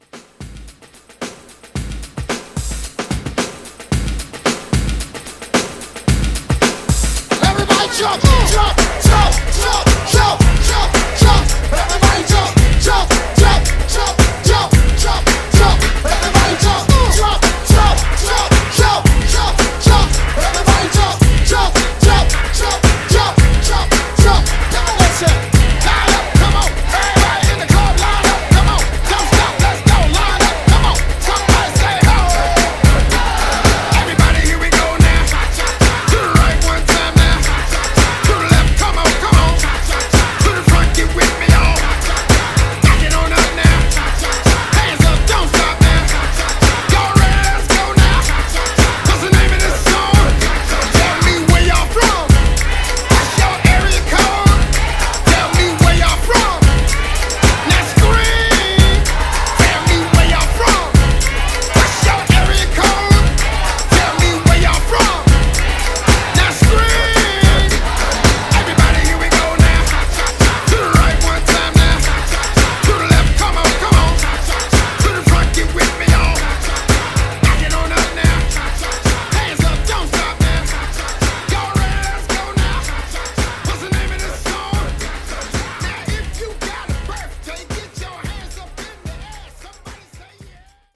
Genres: AFROBEAT , BOOTLEG , DANCE
Clean BPM: 128 Time